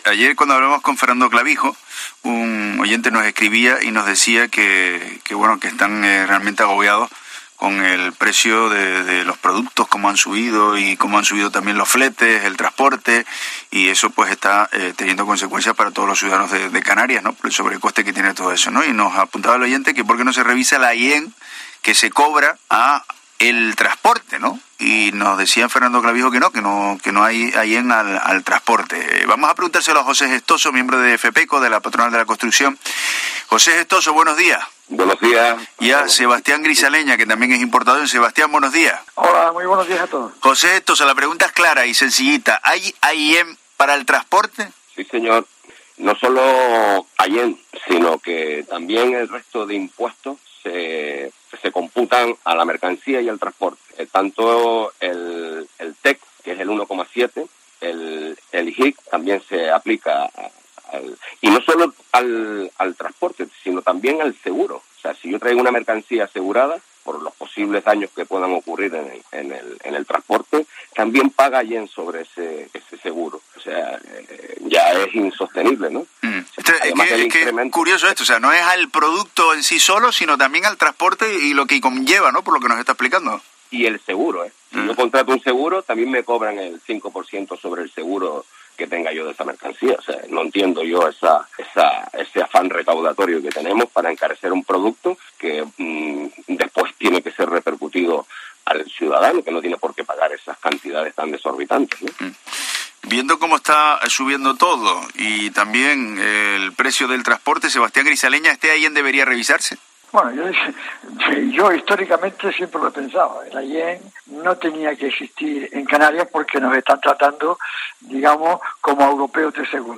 Entrevista La Mañana en Canarias